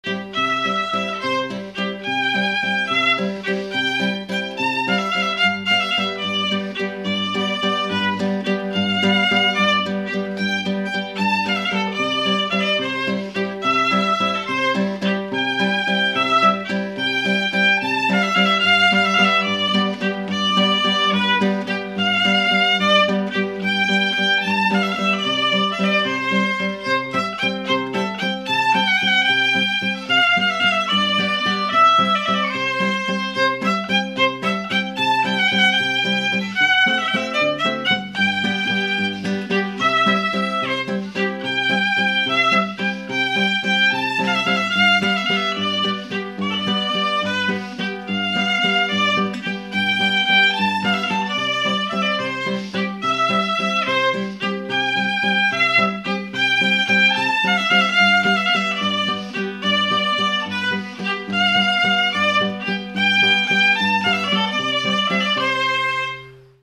Mémoires et Patrimoines vivants - RaddO est une base de données d'archives iconographiques et sonores.
Instrumental
danse : valse
Pièce musicale inédite